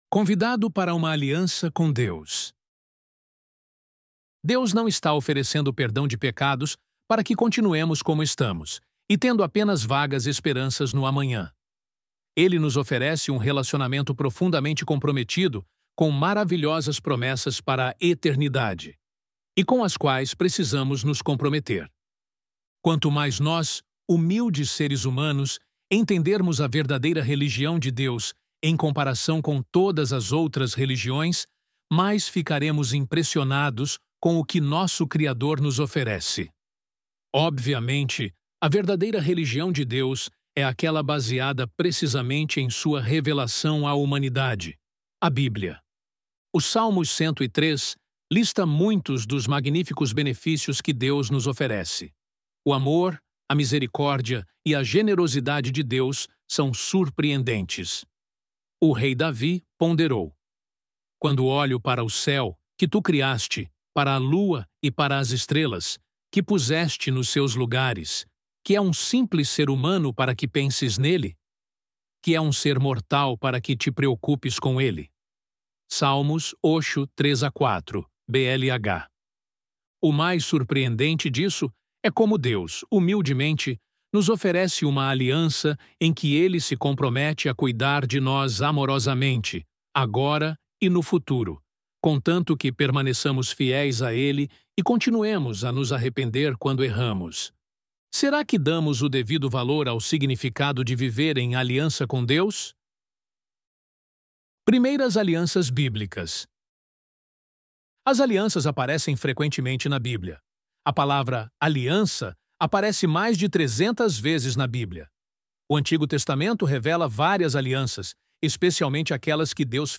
ElevenLabs_Convidado_Para_Uma_Aliança_Com_Deus!.mp3